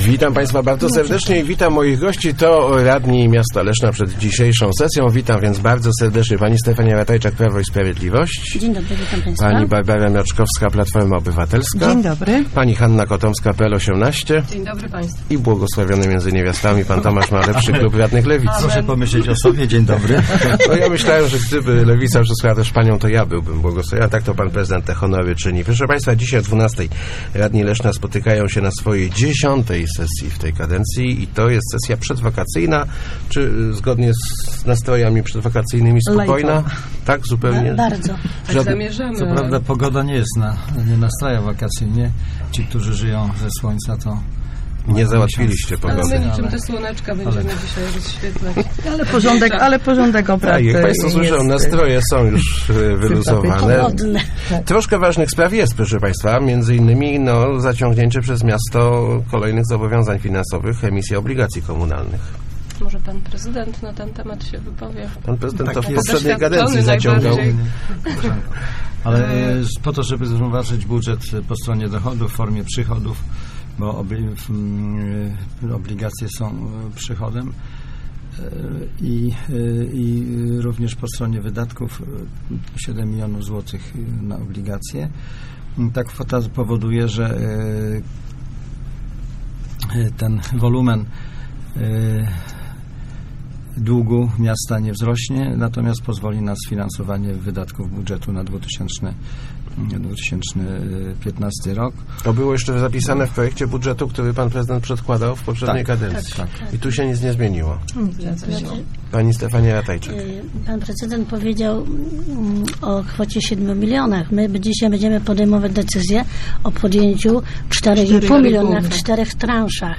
Pomoc finansowa dla Wojewódzkiego Szpitala Zespolonego b�dzie jedn� ze spraw, jakimi na dzisiejszej sesji zajm� si� radni Leszna. Przedstawiciele klubów obecni w Rozmowach Elki zapewnili, �e zag�osuj� za przekazaniem 400 tysi�cy z�otych na porodówk�.